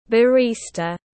Người pha chế cà phê tiếng anh gọi là barista, phiên âm tiếng anh đọc là /bɑːrˈiːs.tə/.
Barista /bɑːrˈiːs.tə/